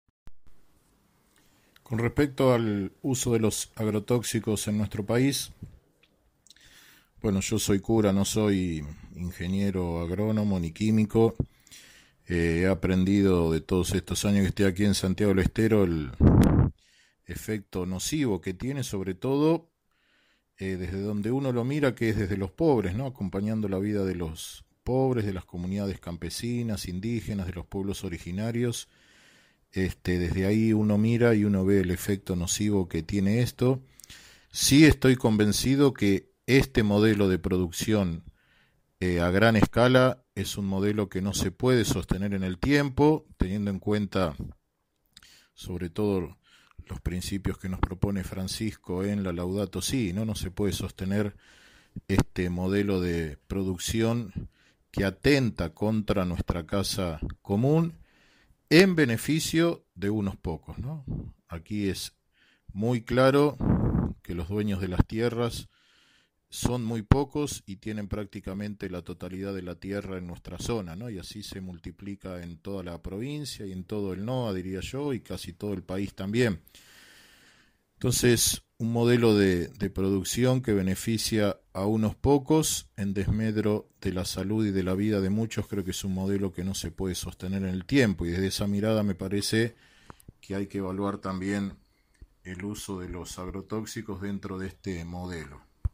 Una entrevista realizada por InfoBaires24 se adentra a la realidad que padecen en Santiago del Estero, los pobladores en zonas donde el uso del agrotóxico y las fumigaciones aéreas se cobra vidas humanas y daños forestales de importancia.